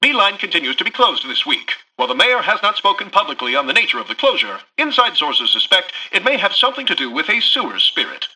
[[Category:Newscaster voicelines]]
Newscaster_headline_47.mp3